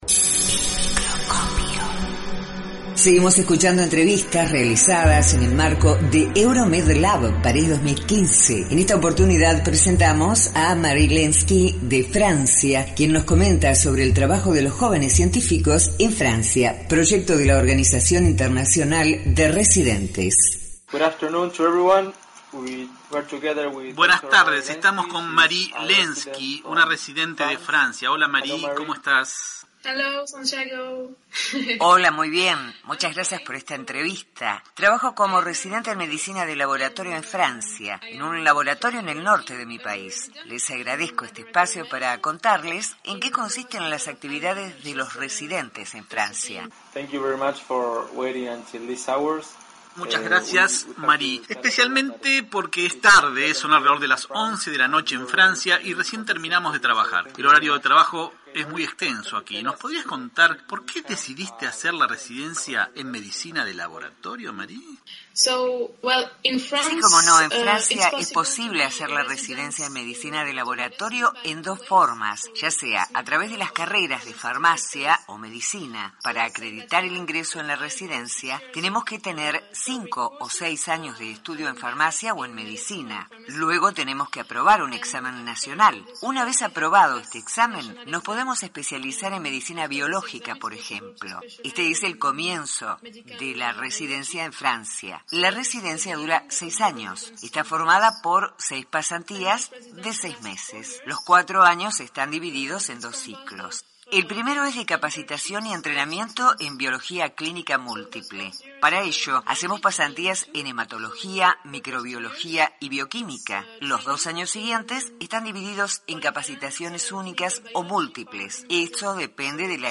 Entrevista
Seguimos presentando las entrevistas realizadas en el marco de Euromedlab, París 2015.